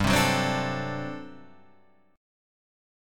GM7sus2sus4 chord